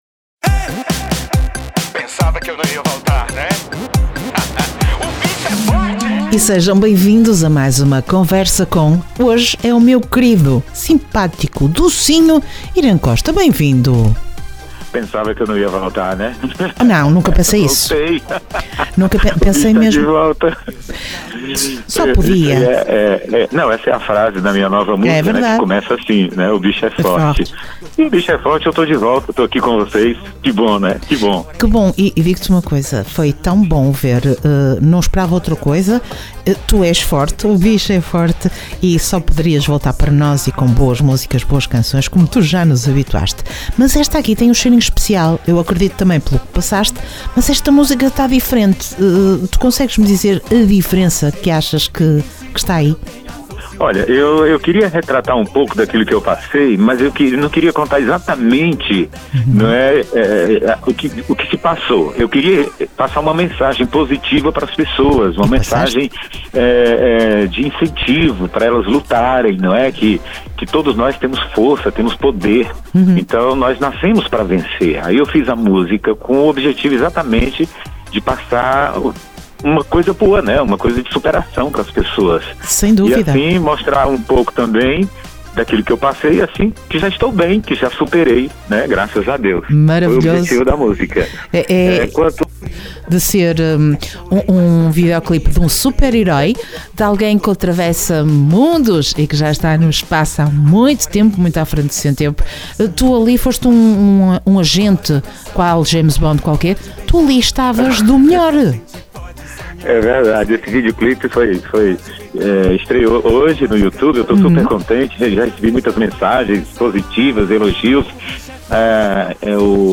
Entrevista Iran Costa dia 06 de março.